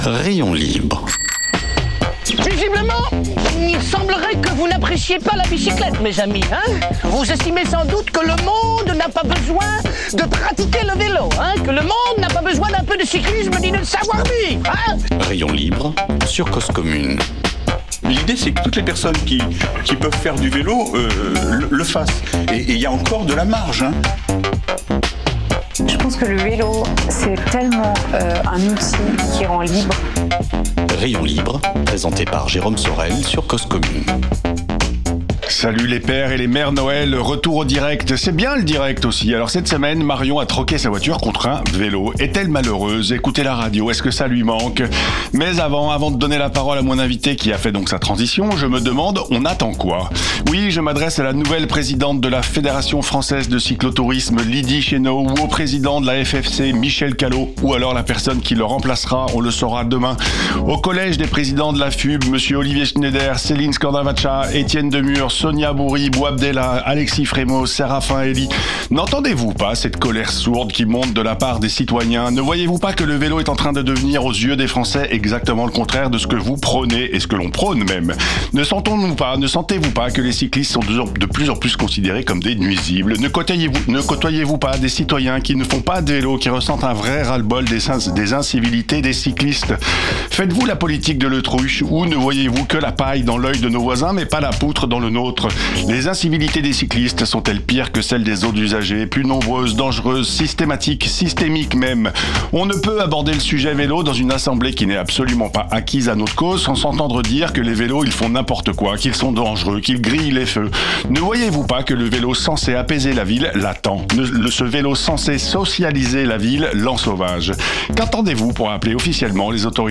En plateau